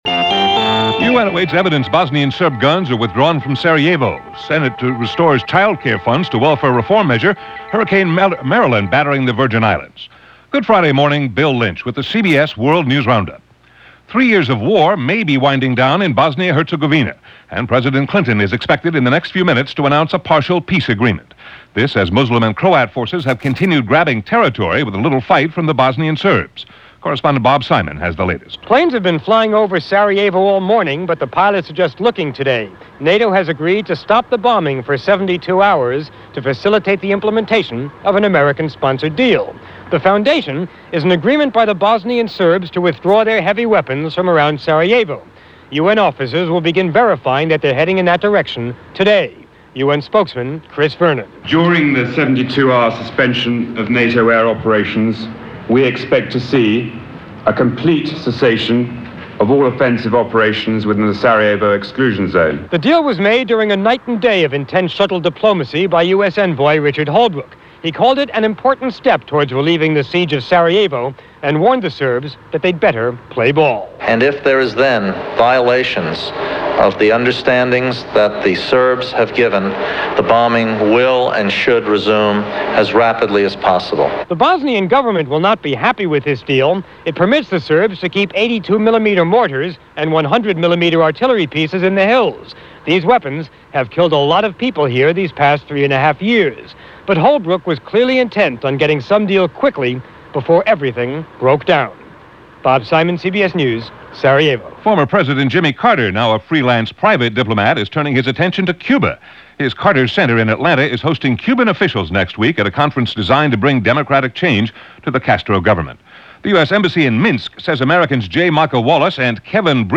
And that’s a small portion of what went on in the world, this September 15, 1995 as reported by The CBS World News Roundup.